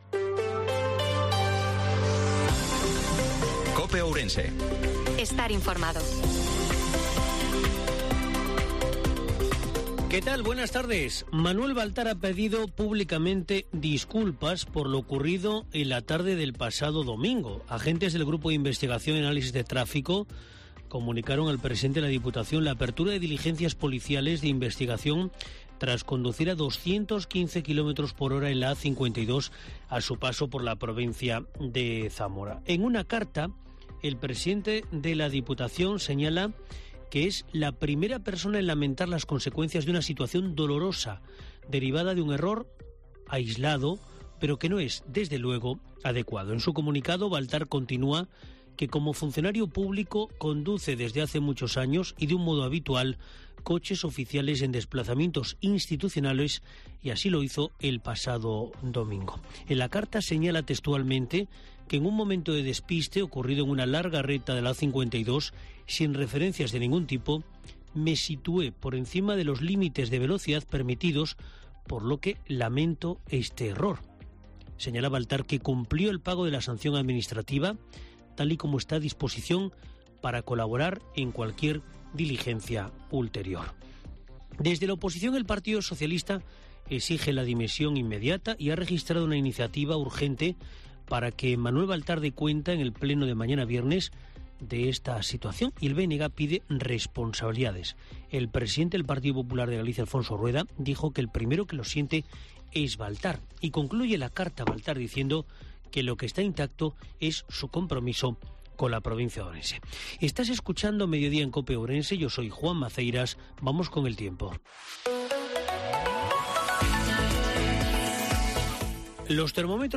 INFORMATIVO MEDIODIA COPE OURENSE-27/04/2023